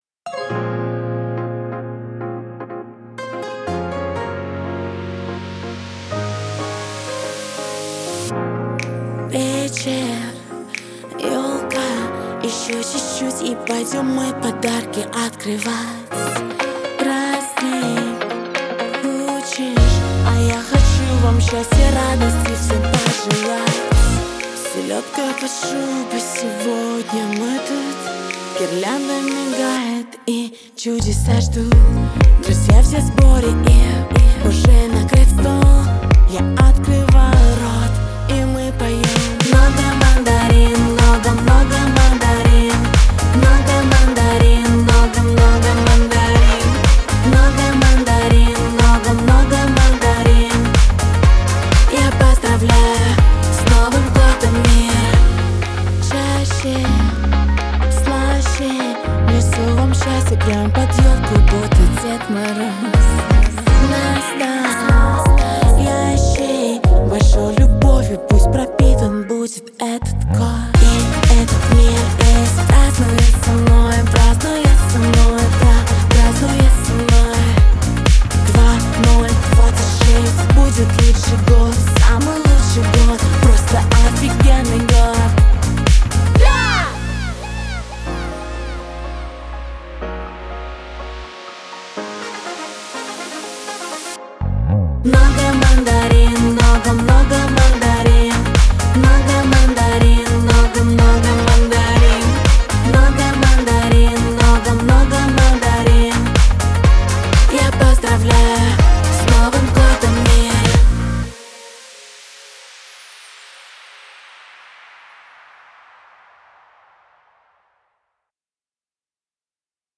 Категория: Хип-Хоп